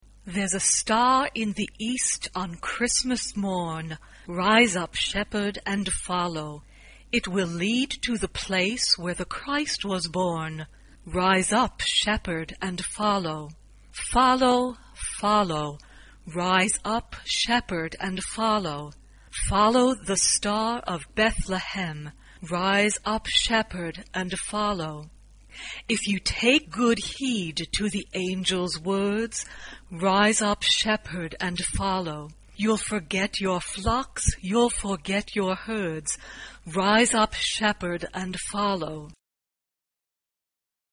SA (2 voix égale(s) d'enfants) ; Partition complète.
Chant de Noël.
Spiritual Afro-Américain. Gospel.
Instruments : Piano (1)
Tonalité : mi bémol majeur